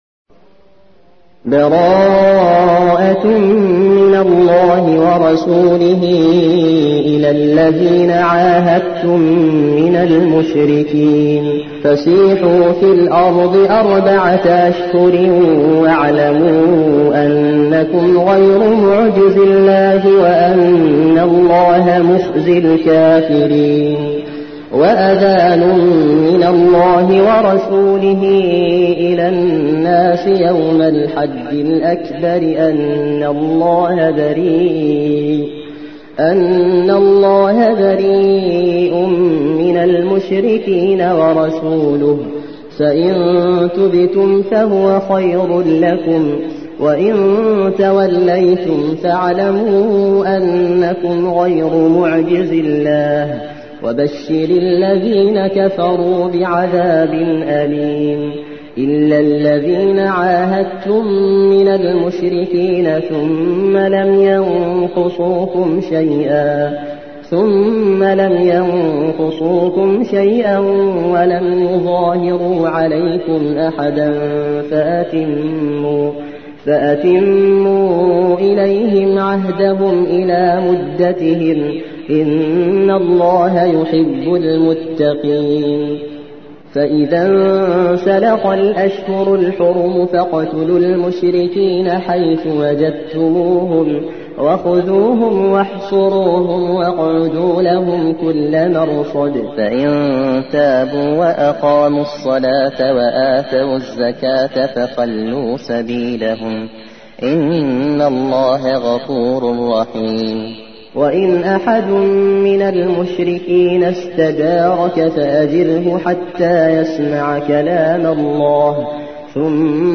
9. سورة التوبة / القارئ